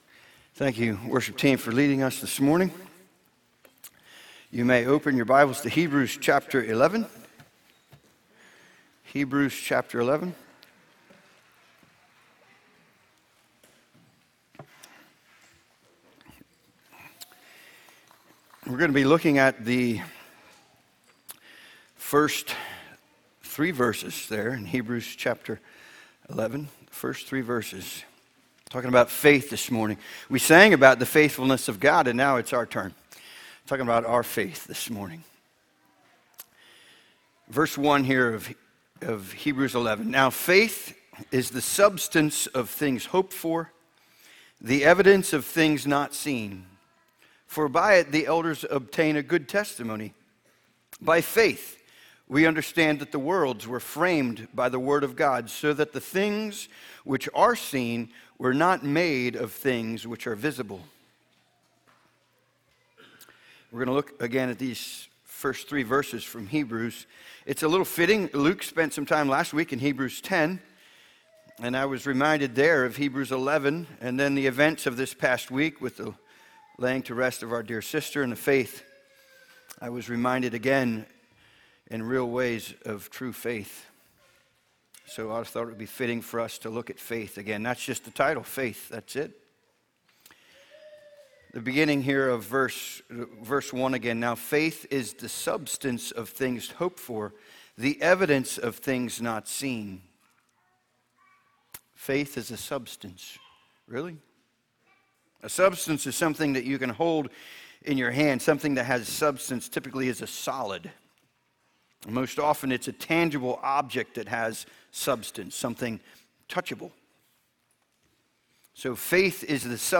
Sermon Archive | - New Covenant Mennonite Fellowship
From Series: "Sunday Morning - 10:30"